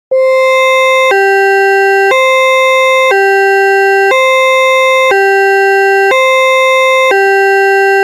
SE（サイレン）